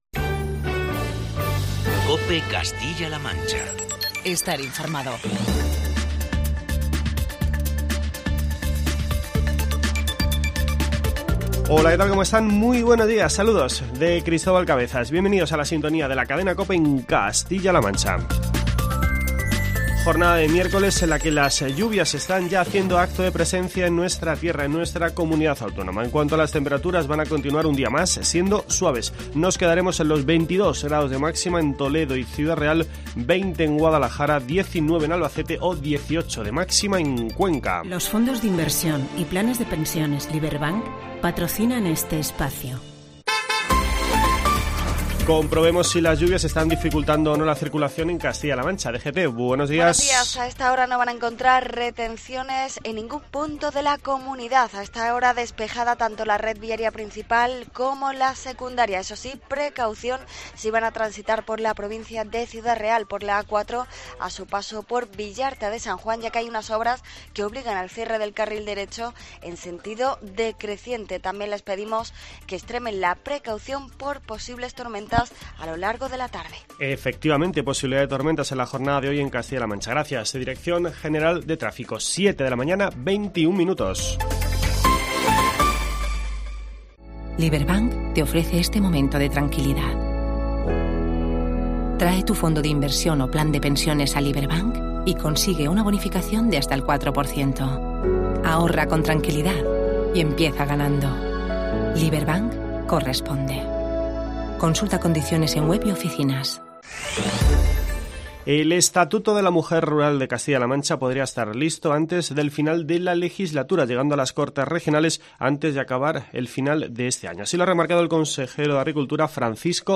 El consejero ha dicho en una rueda de prensa que el Gobierno regional tiene clara la "importancia" de afrontar las cosas "con contundencia, de verdad y cumplir", por eso el Estatuto de las Mujeres Rurales de Castilla-La Mancha tendrá un "enfoque feminista" al igual que las políticas de la Consejería.